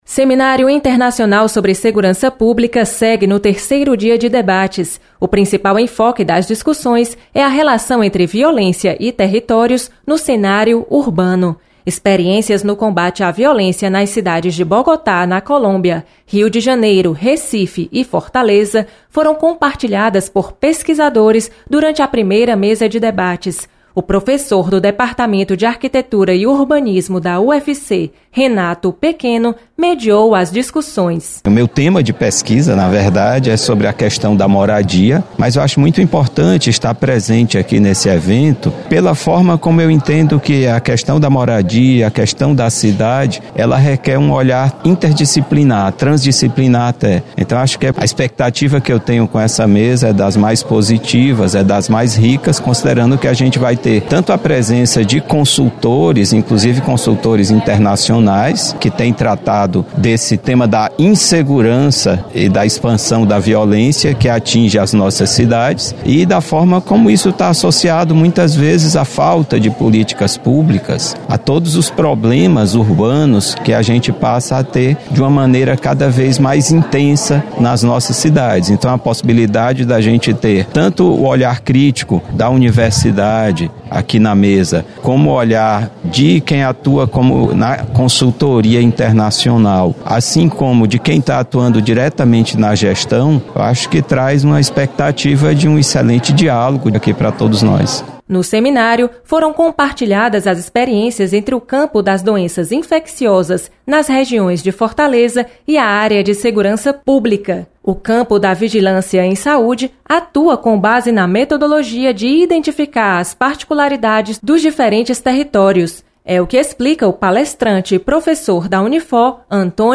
Violência e território no cenário urbano  é tema do terceiro dia de discussões do Seminário Internacional sobre Violência Pública.  Repórter